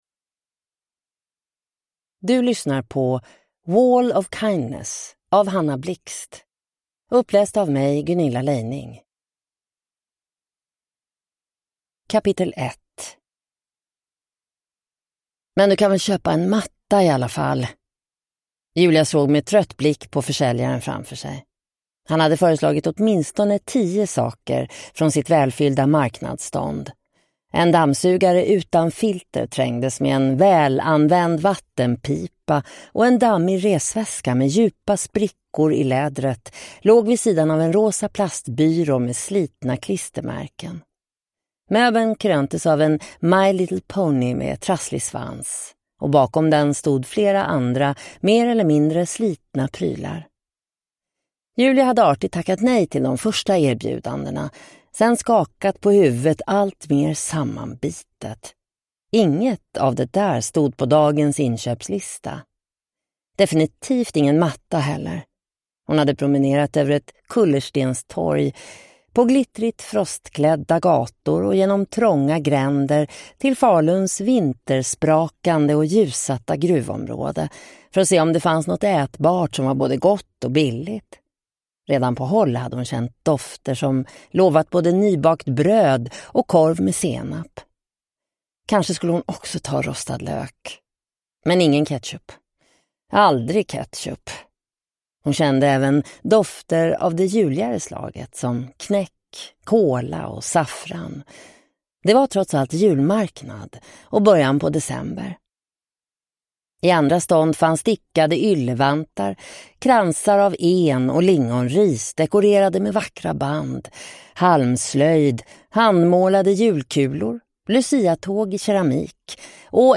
Wall of kindness : med toner av kärlek (ljudbok) av Hanna Blixt